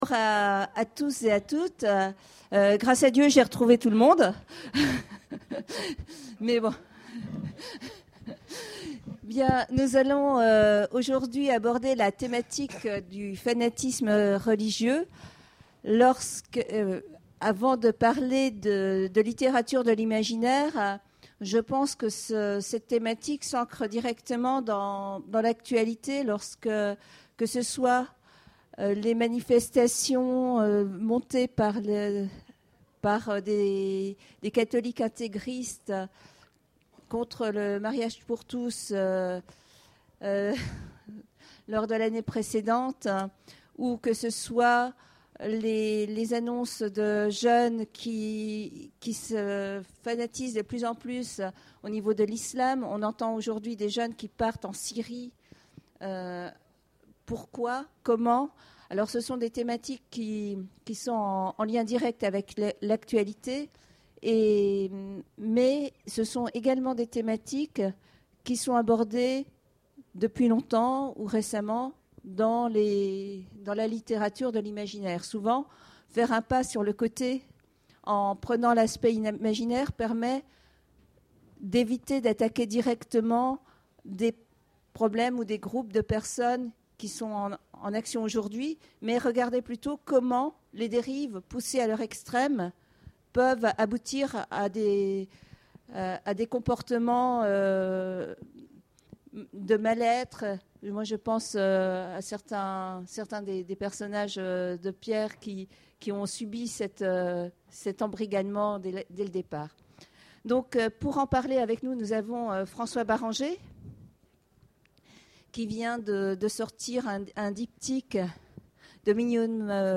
Imaginales 2014 : Conférence Fanatiques religieux...